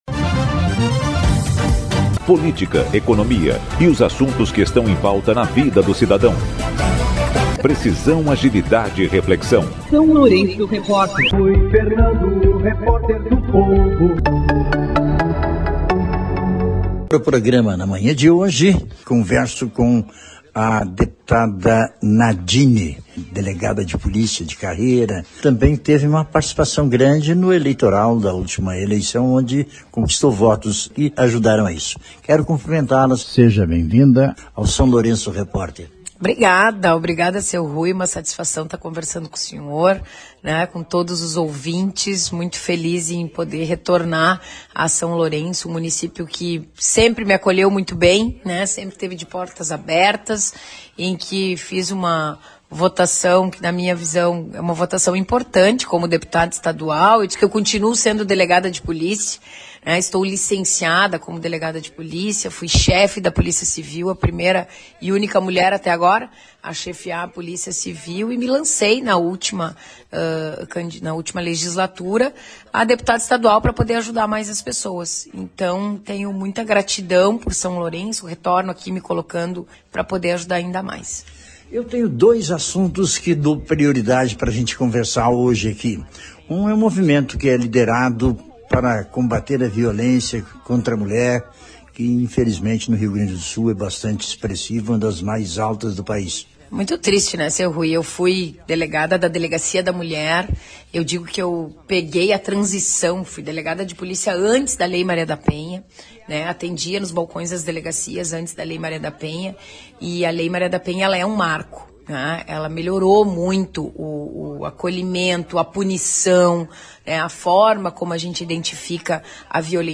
O SLR conversou ao longo do final de semana com a deputada estadual Delegada Nadine Anflor (PSDB), pré-candidata pelo PSD. Na oportunidade, ela falou sobre suas ações e a necessidade de mudanças culturais no enfrentamento à violência contra a mulher.
ENTREVISTA-16.3-Deputada-Nadine.mp3.mp3